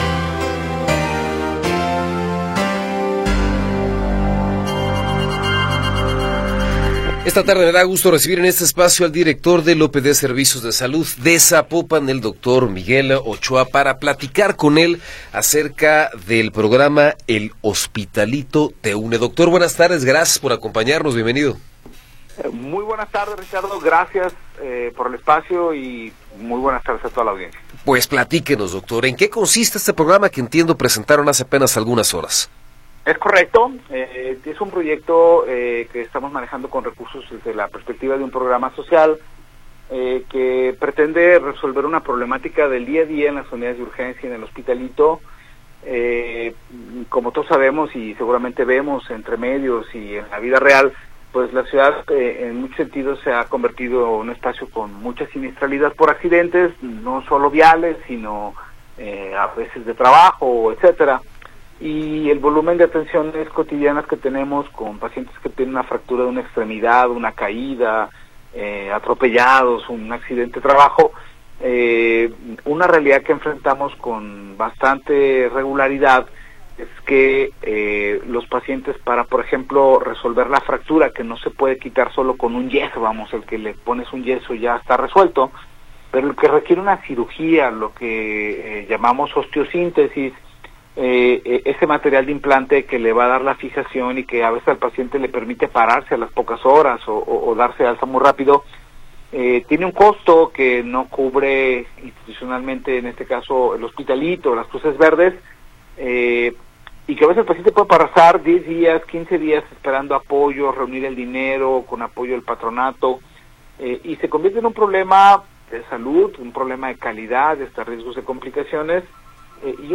Entrevista con Miguel Ochoa Plascencia